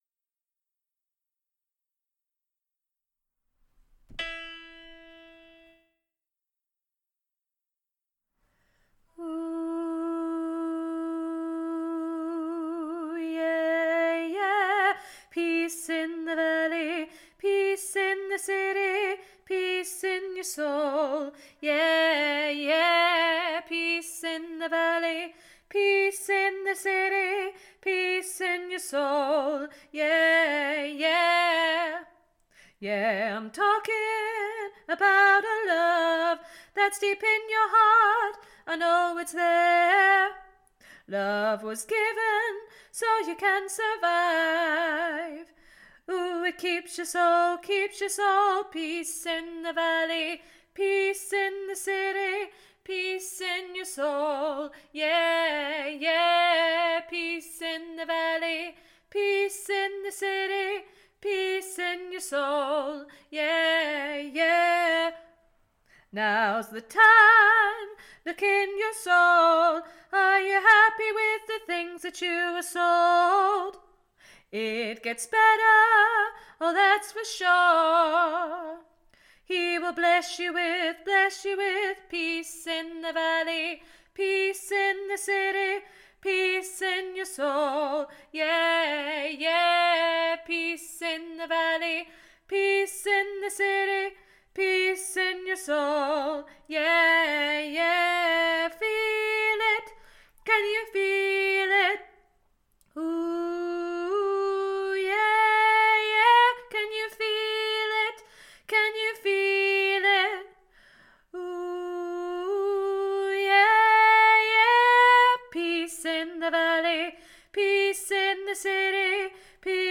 Peace 3VG Revamp ALTO ONLY